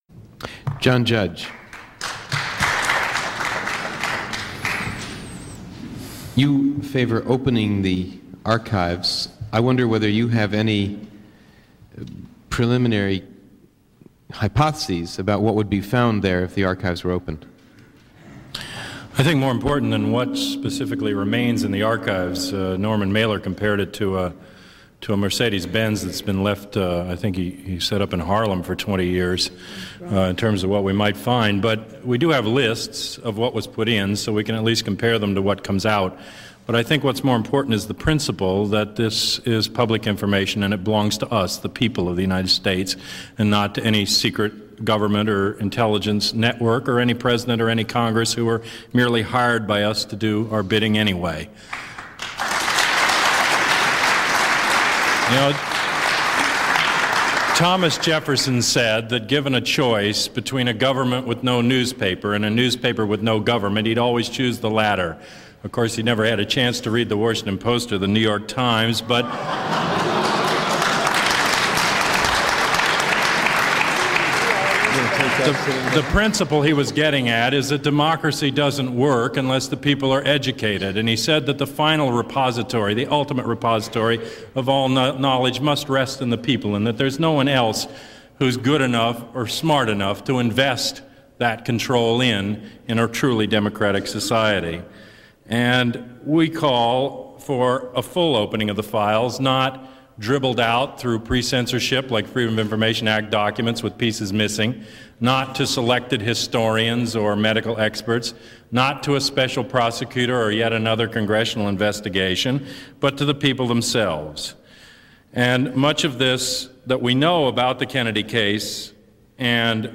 This is an excerpt from a Forum held at the American University School of Communication broadcast by C-SPAN2.